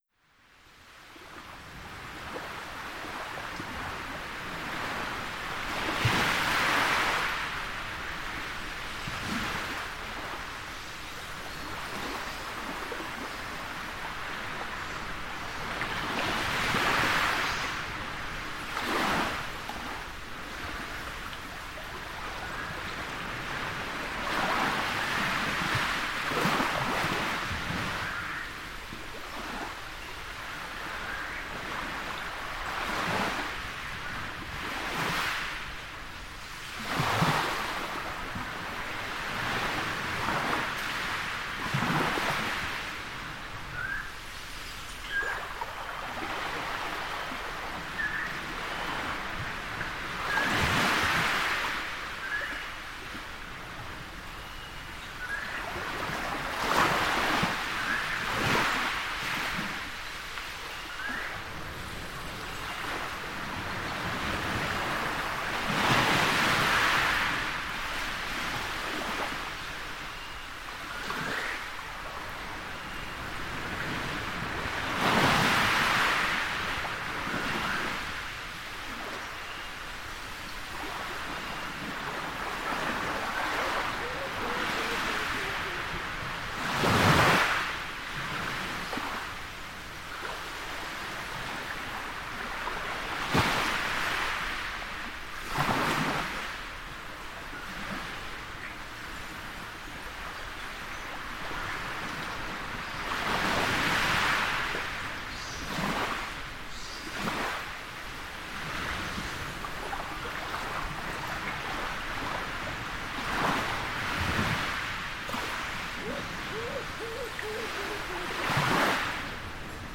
• ocean waves on a tropical coastline.wav
ocean_waves_on_a_tropical_coastline_cy8.wav